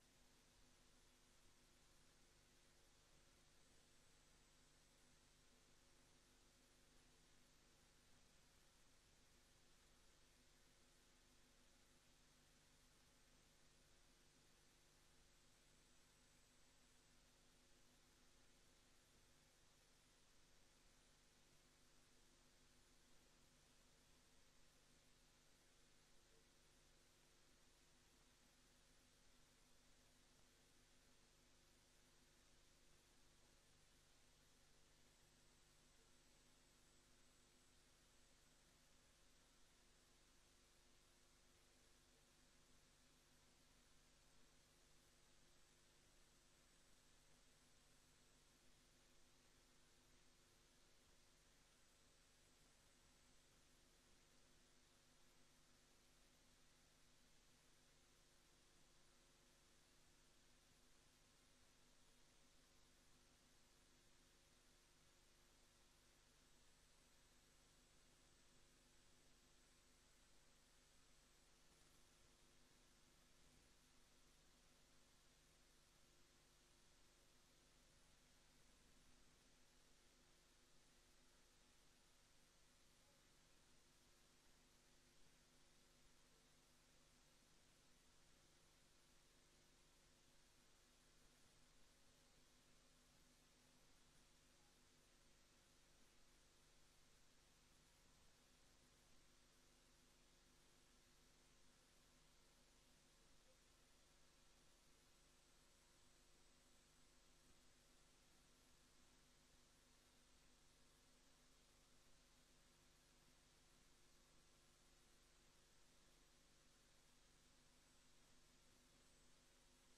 Raadsvergadering Papendrecht 06 juli 2023 15:00:00, Gemeente Papendrecht